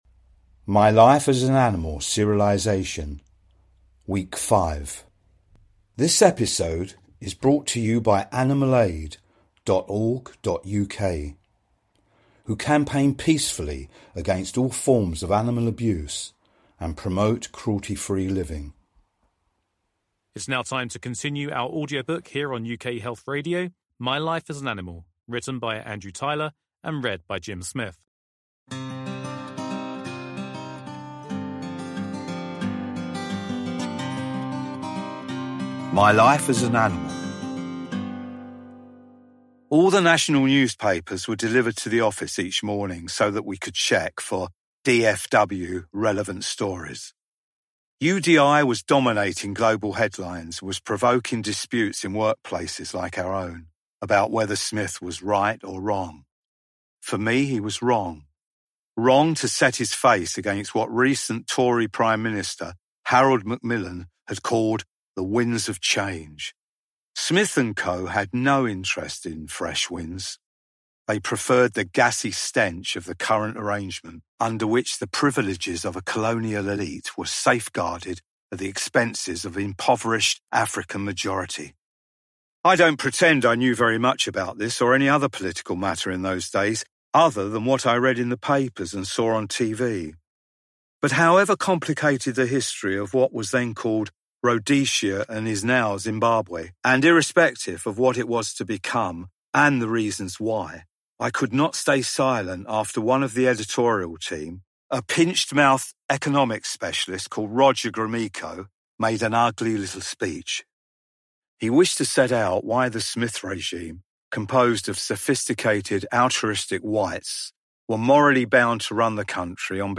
serialisation
It is beautifully written and sensitively voiced.